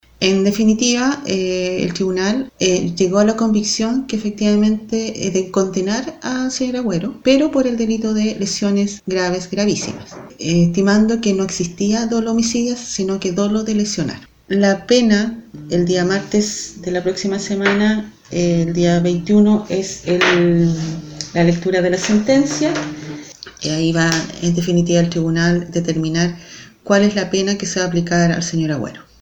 El Tribunal lo condenó como autor del delito de lesiones graves gravísimas, según indicó la fiscal María Inés Núñez.
16-FISCAL-MARIA-INÉS-NUÑEZ.mp3